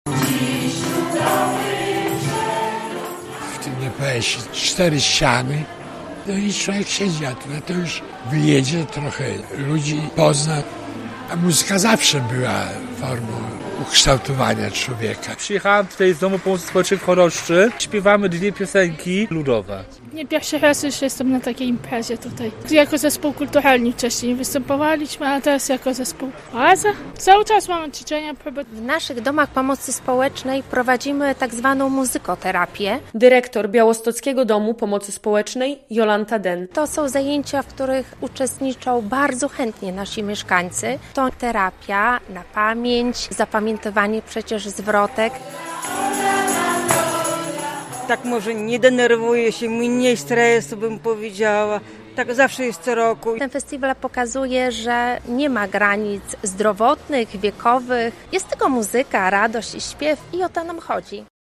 To okazja do wspólnej zabawy, śpiewów i integracji. Mieszkańcy podlaskich domów pomocy społecznej wzięli w środę (5.06) udział w 3. edycji Wojewódzkiego Przeglądu Piosenki "Na Ludową Nutę".